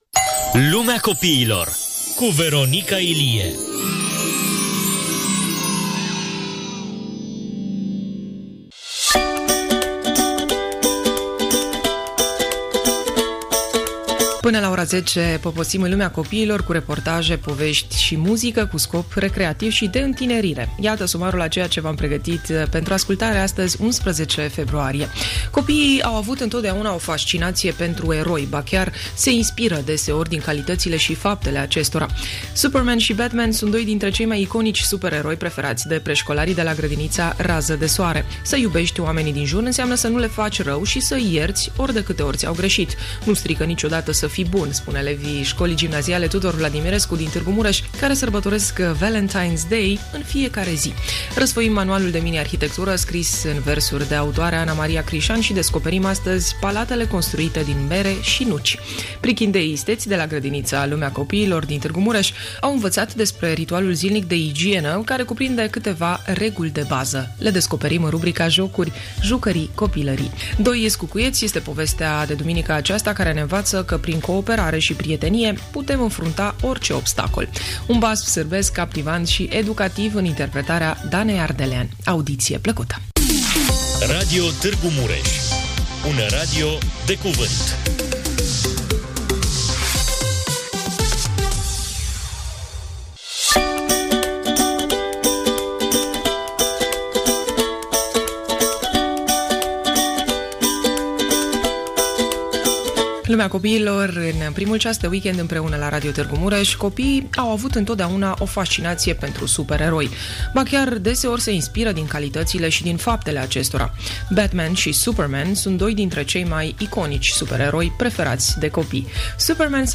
Dimineața de duminică începe cu o incursiune în „Lumea copiilor”, cu reportaje, povești și muzică, dedicate tuturor copiilor și celor care își păstrează spiritul tânăr. Copiii au avut întotdeauna o fascinație pentru eroi, ba chiar se inspiră din calitățile și faptele acestora.